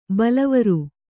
♪ balavaru